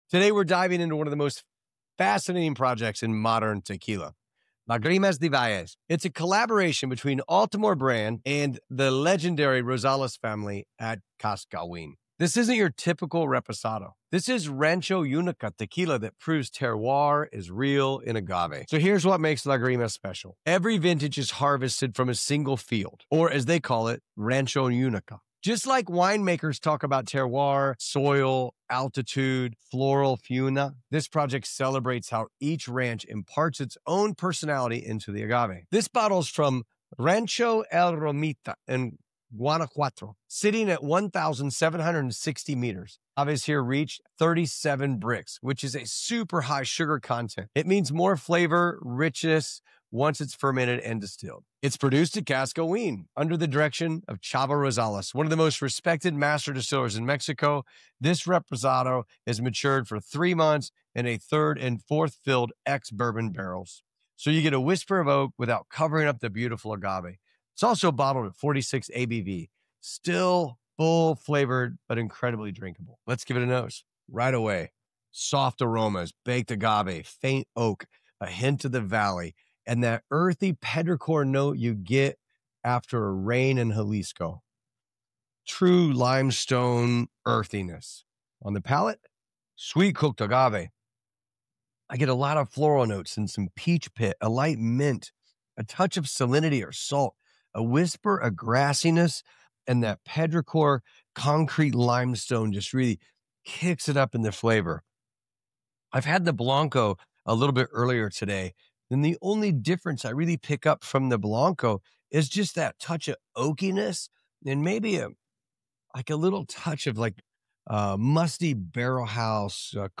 Each episode, we bring you candid conversations with master distillers, brand founders, and agave experts who share their stories, craft secrets, and passion for tequila. Whether you’re a seasoned aficionado or just beginning your tequila journey, join us as we explore the rich culture, traditions, and innovations shaping this iconic spirit.